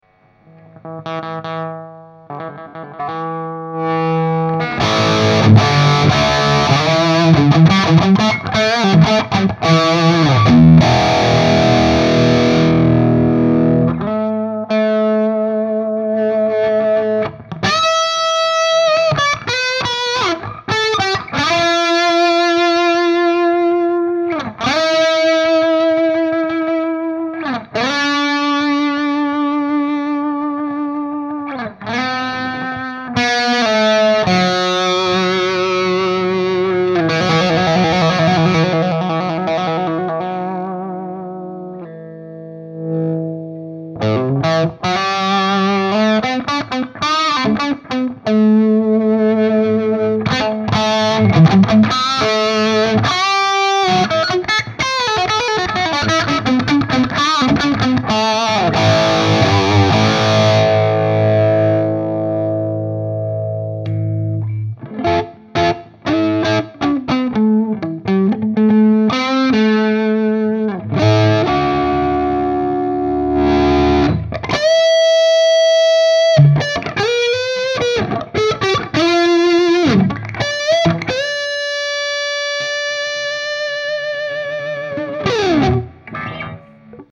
Re: ЕЩЕ. ЕЩЕ. Семплы Динамиков Fane, Eminence, Jensen, WGS.
никаких студийных тонтестов. Домашний семплик. Проверка усилителя на динамику  ;D